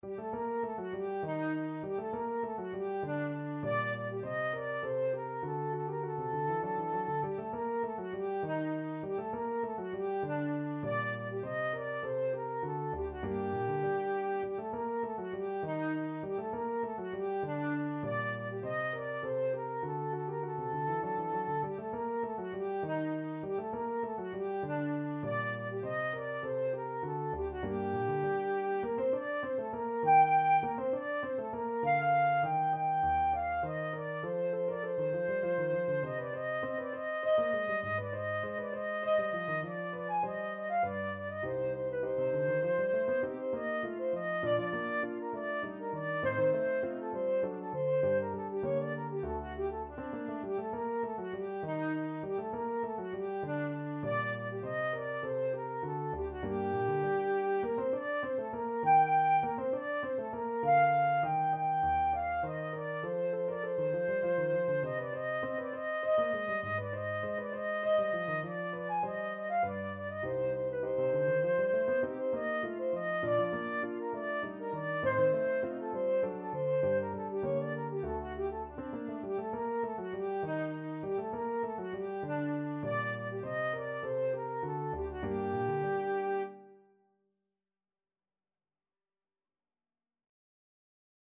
3/4 (View more 3/4 Music)
Allegretto = 100
Classical (View more Classical Flute Music)